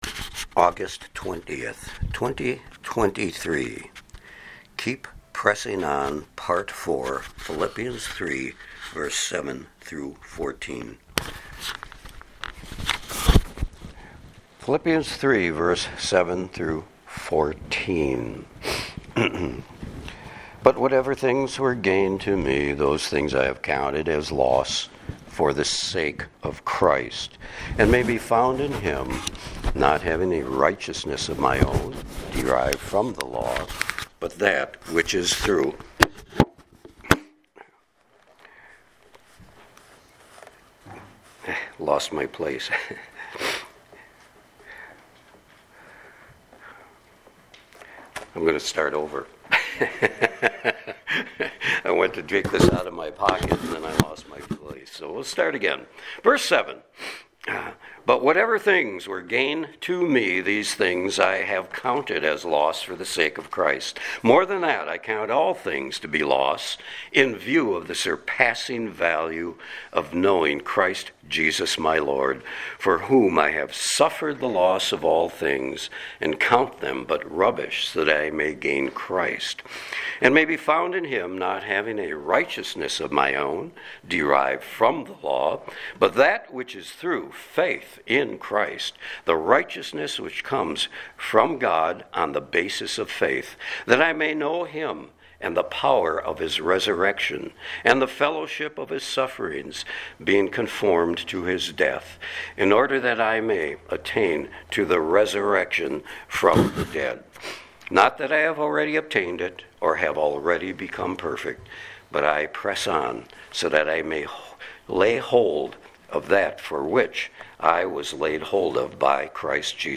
Sermons | Sand Lake Chapel
Guest Speaker